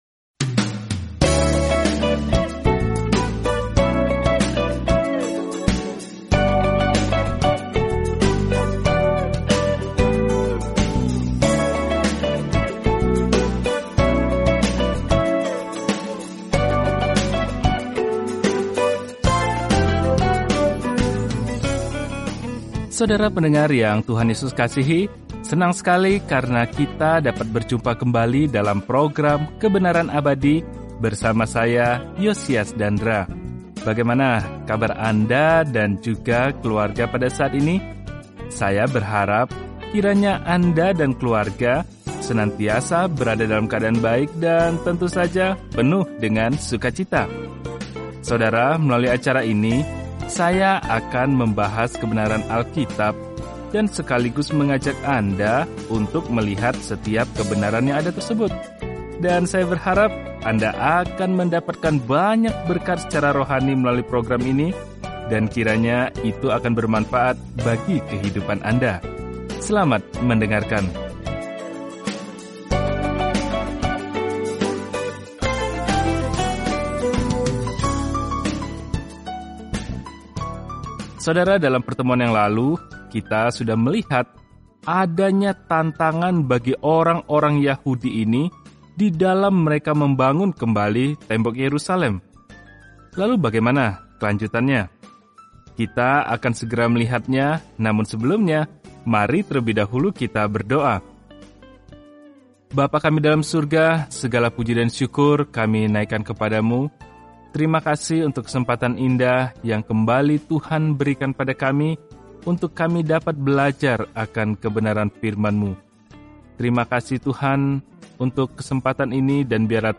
Firman Tuhan, Alkitab Nehemia 5 Hari 6 Mulai Rencana ini Hari 8 Tentang Rencana ini Ketika Israel kembali ke tanah mereka, kondisi Yerusalem buruk; seorang manusia biasa, Nehemia, membangun kembali tembok di sekeliling kota dalam buku Sejarah terakhir ini. Telusuri Nehemia setiap hari sambil mendengarkan pelajaran audio dan membaca ayat-ayat tertentu dari firman Tuhan.